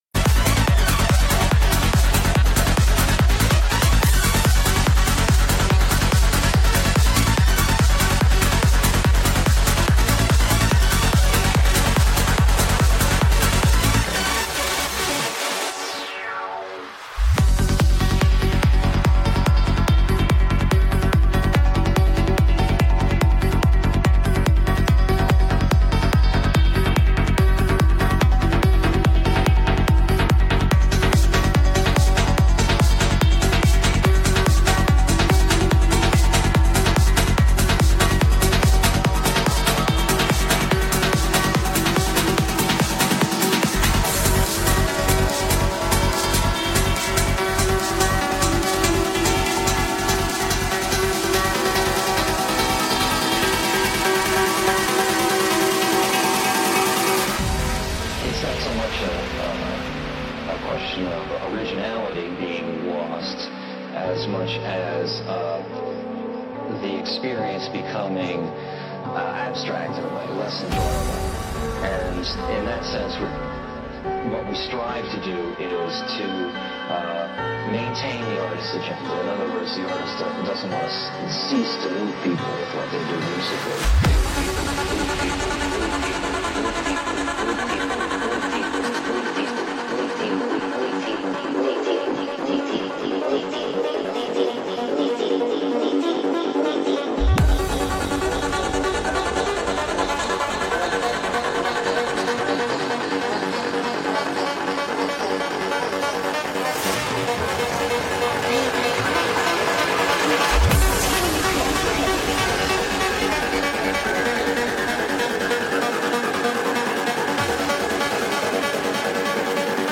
Also find other EDM
Liveset/DJ mix